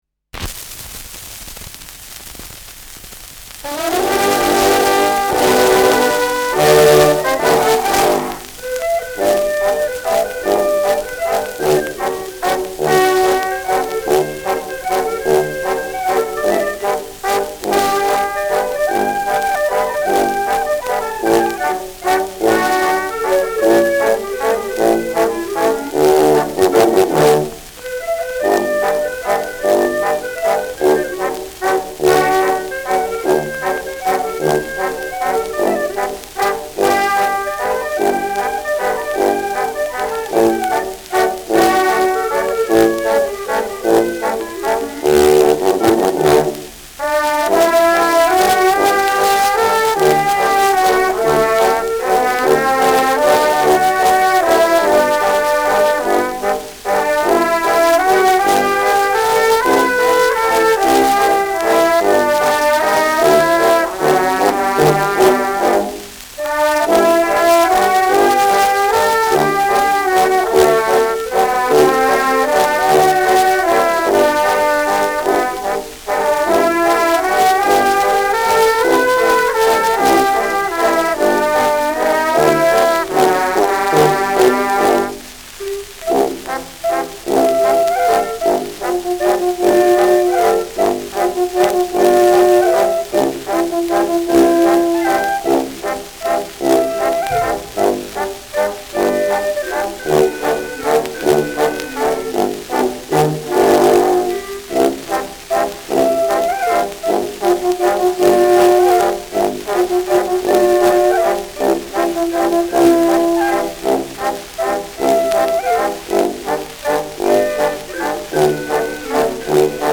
Schellackplatte
leichtes Rauschen : präsentes Knistern : leichtes Leiern
[unbekanntes Ensemble] (Interpretation)